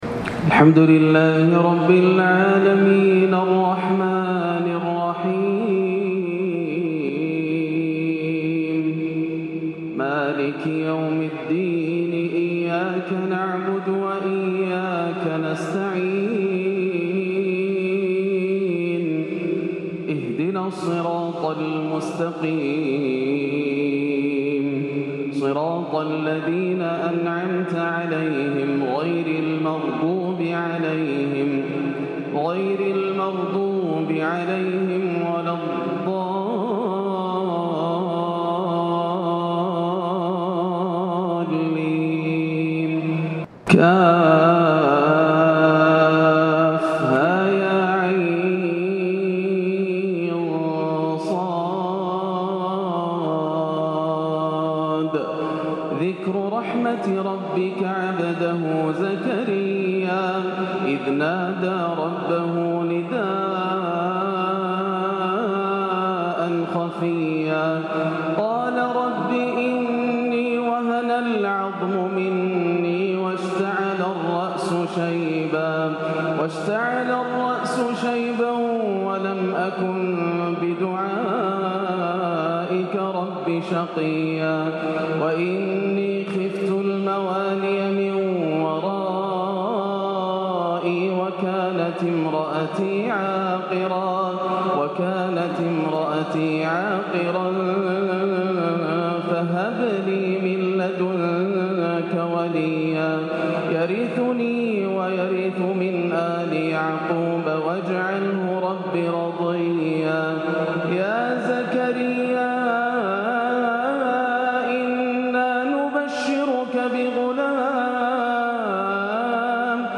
عشائية يصعب وصفها استفتاح سورة "مريم" بالصبا والكرد يوم الثلاتاء 3-7 > عام 1439 > الفروض - تلاوات ياسر الدوسري